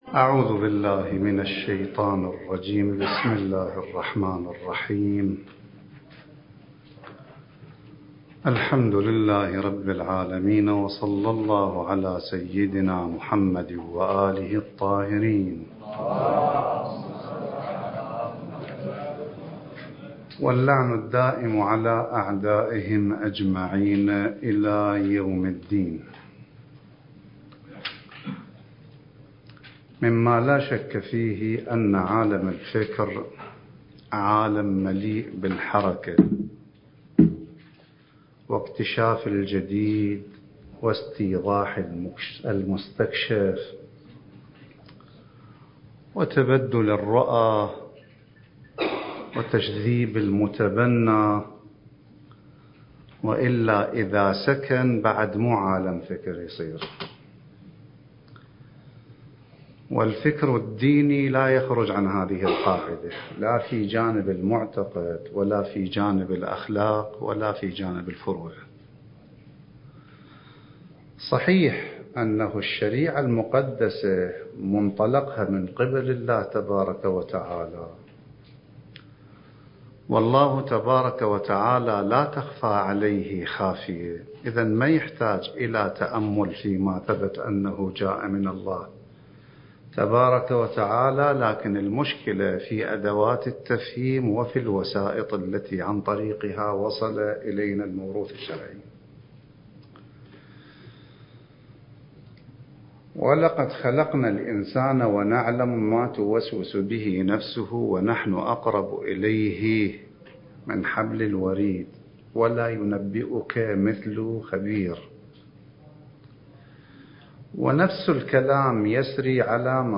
الدورة المهدوية الأولى المكثفة (المحاضرة الخامسة عشر)
المكان: النجف الأشرف